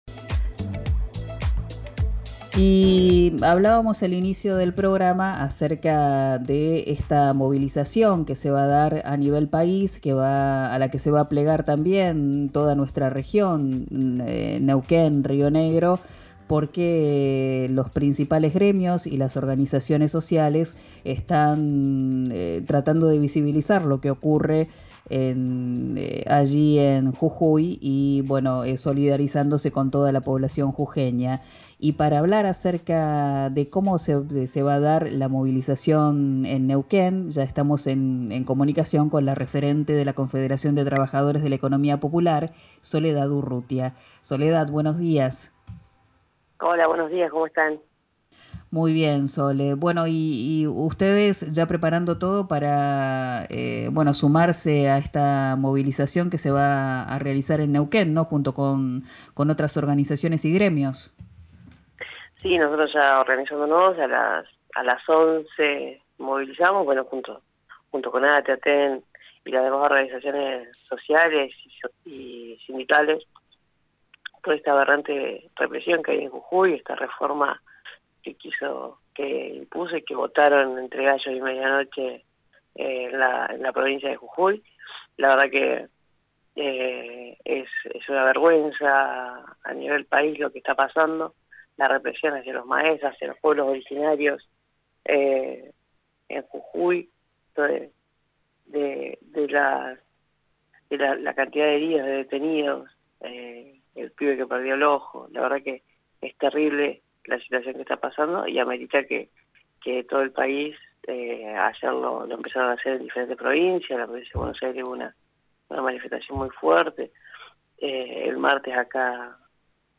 El camión garrafero no pasa y por esto algunos vecinos decidieron el día de ayer cortar un acceso para exigir respuestas. La Confederación de Trabajadores de las Economías Populares habló en RIO NEGRO RADIO acerca de la situación en los barrios del oeste la ciudad.